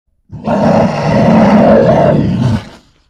Звуки гризли
Рык зверя